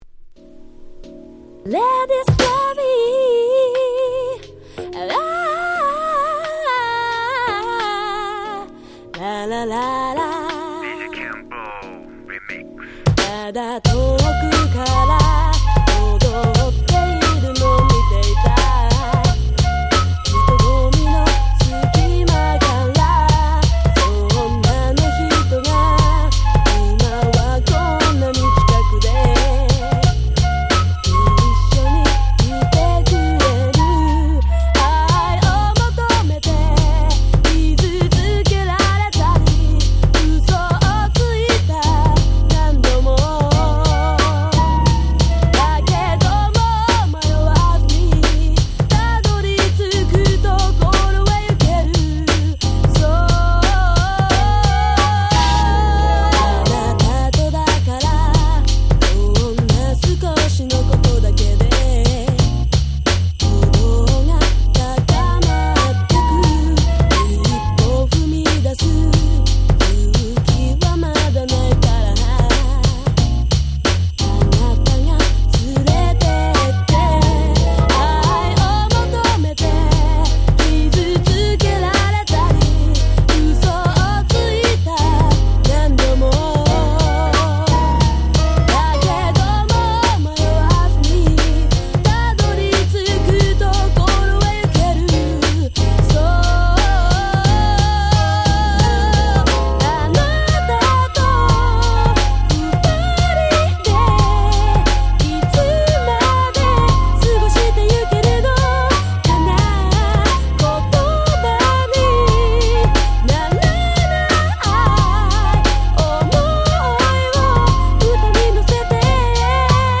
00' Nice Japanese R&B !!